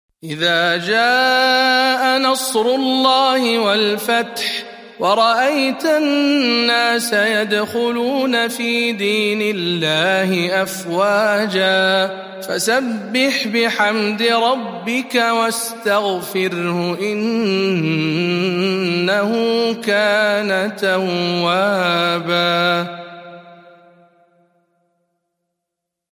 سورة النصر برواية شعبة عن عاصم - رمضان 1441 هـ